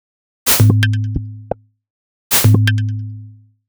Salamanderbrain2 130bpm.wav